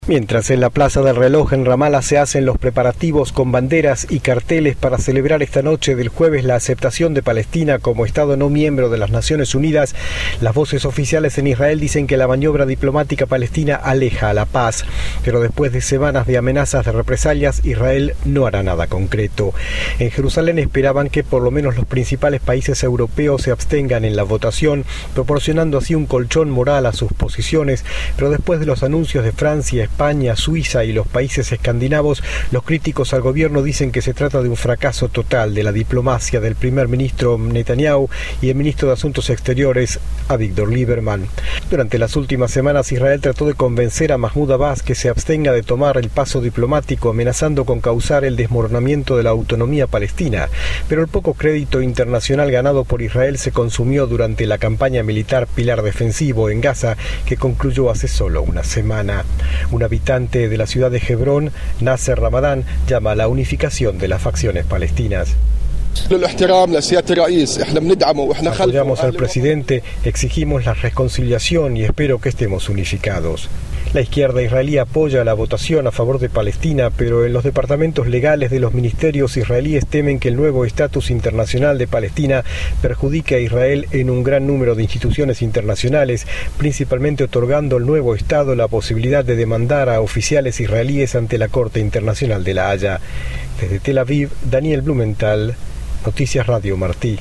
Reportaje